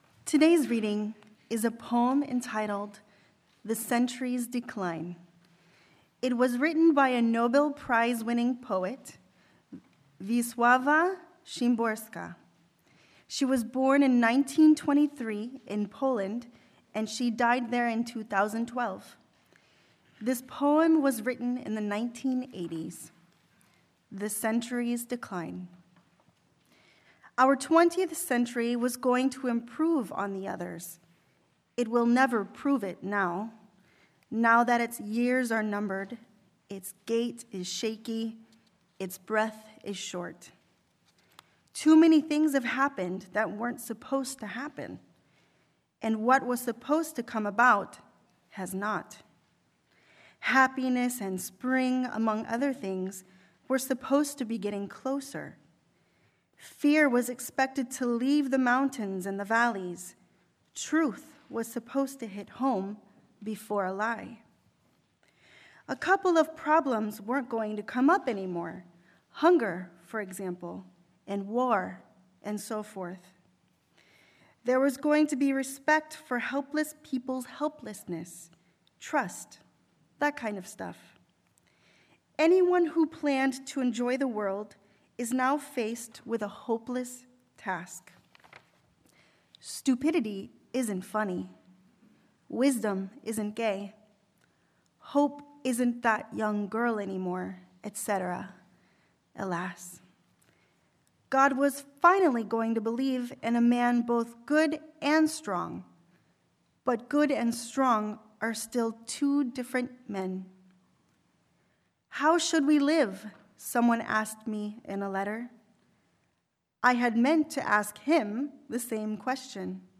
0:00 – 2:13 — Reading 1 2:13 – 4:13 — Reading 2 4:13 – 7:00 — Hymn “Turn Back, Turn Back” 7:00 – 27:13 — Sermon